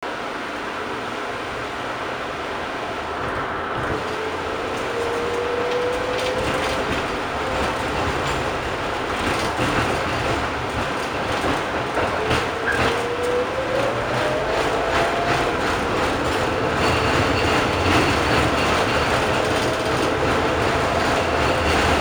Звуки трамвая
Скрип колес старого трамвая на повороте